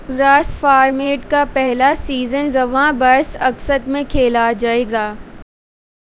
Spoofed_TTS/Speaker_10/13.wav · CSALT/deepfake_detection_dataset_urdu at main
deepfake_detection_dataset_urdu / Spoofed_TTS /Speaker_10 /13.wav